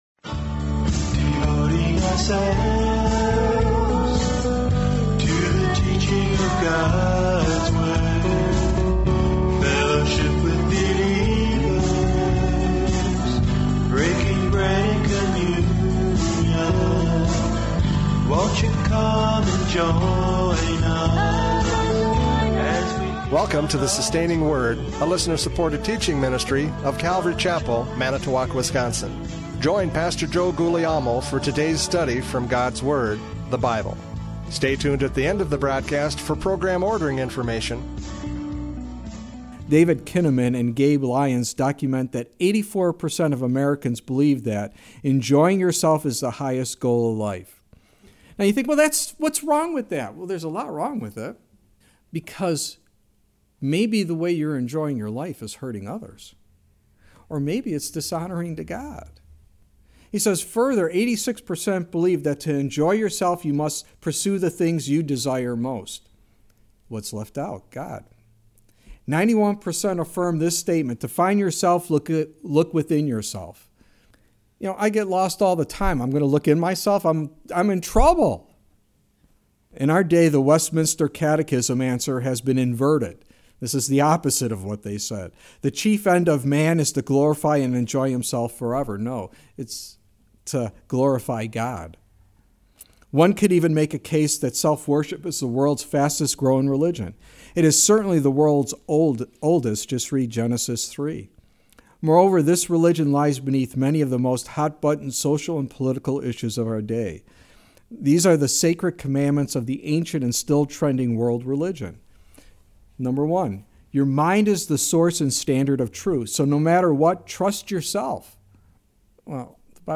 John 12:23-43 Service Type: Radio Programs « John 12:23-43 The Messiah Teaches!